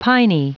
Prononciation du mot piny en anglais (fichier audio)
Prononciation du mot : piny